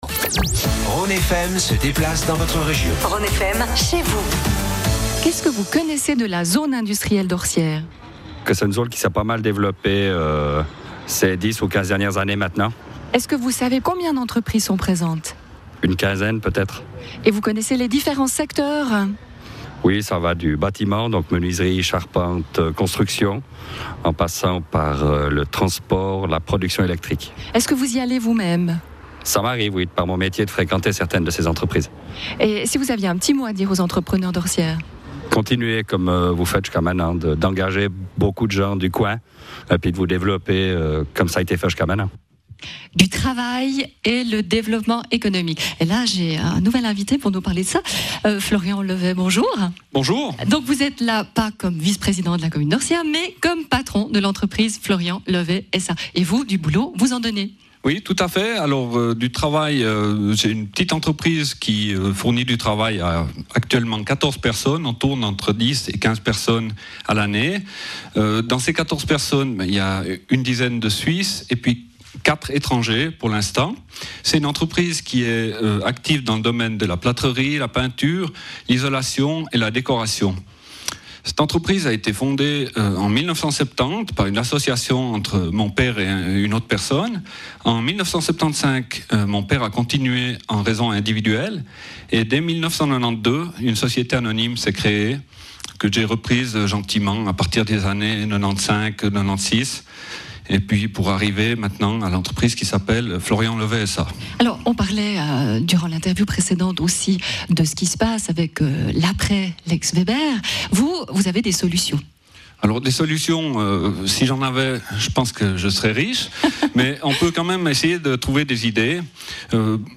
Après une présentation de sa zone industrielle par le Président de commune, les interviews en direct de plusieurs entrepreneurs vous permettront de mieux connaitre une commune et son économie !
Interview de M.